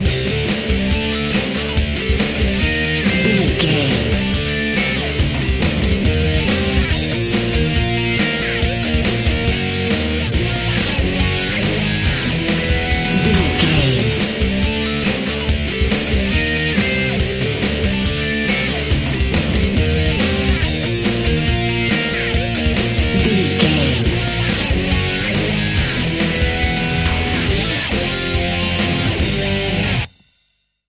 Aeolian/Minor
drums
electric guitar
bass guitar
pop rock
hard rock
aggressive
energetic
intense
nu metal
alternative metal